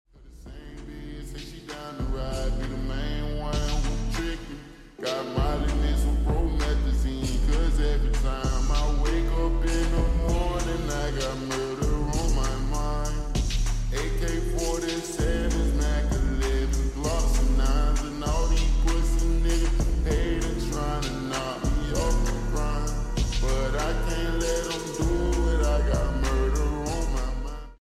Slowed/8D